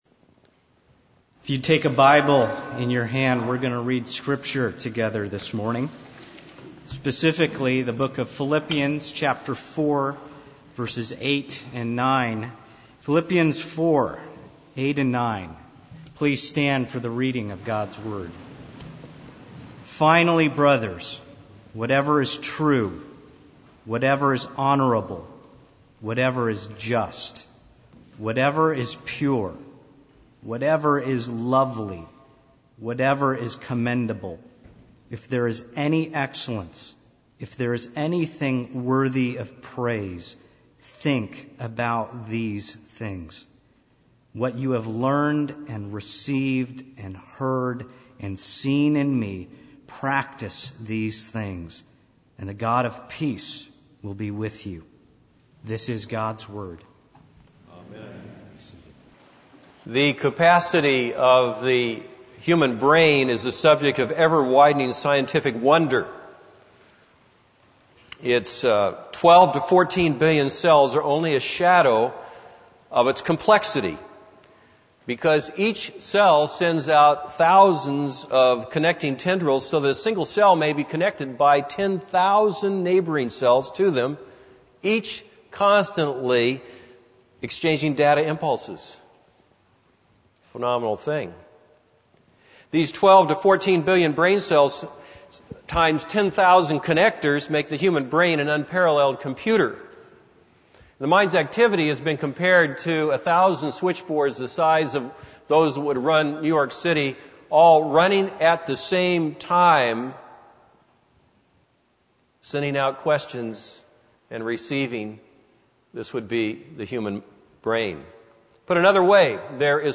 This is a sermon on Philippians 4:8-9.